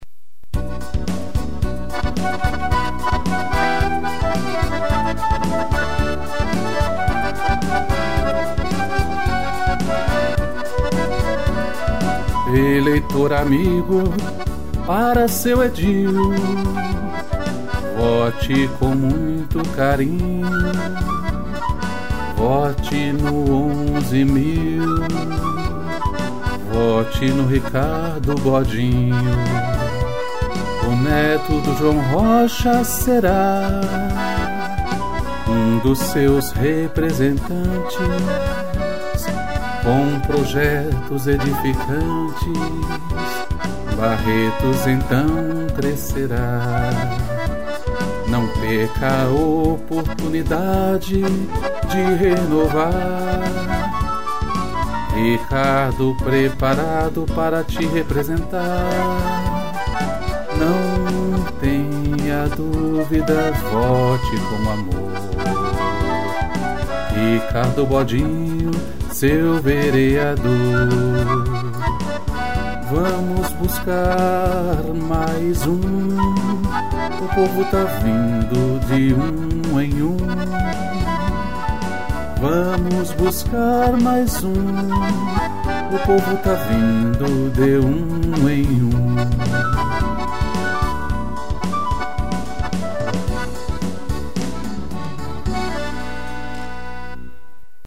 acordeão e flauta